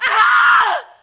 scream_woman_1.ogg